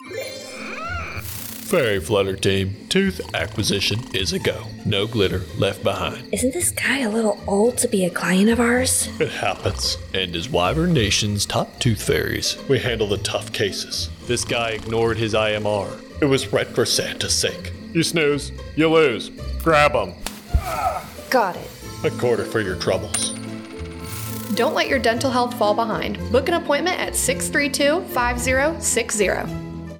A radio spot reminding service members to stay up-to-date on their dental readiness at Aviano Air Base, Italy. Maintaining an up-to-date dental readiness classification is essential as it directly impacts their medical readiness status and ability to deploy.